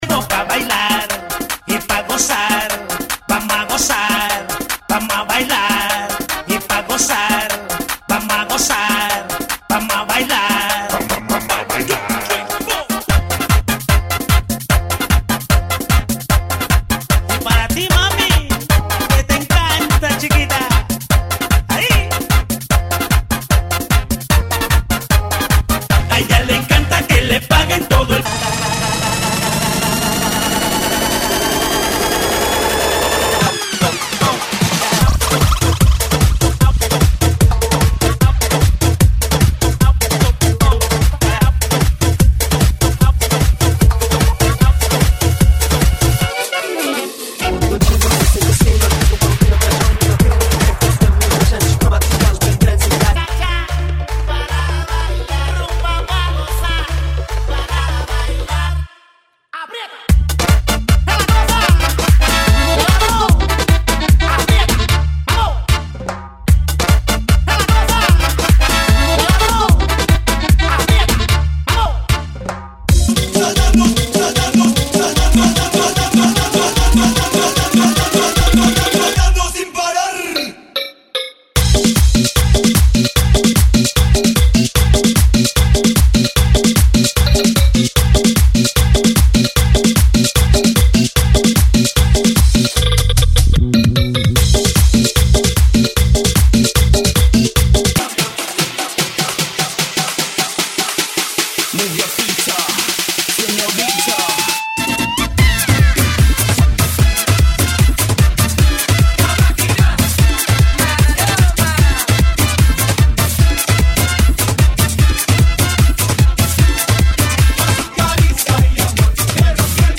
GENERO: LATINO HOUSE